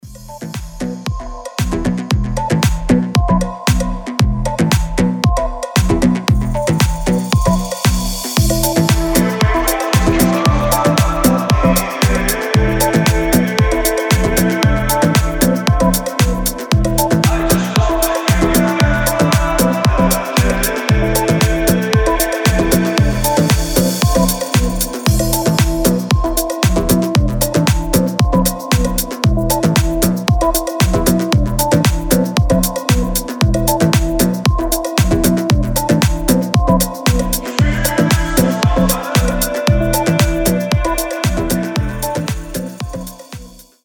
• Качество: 320, Stereo
deep house
Electronic
EDM
Такой ненавязчивый ритм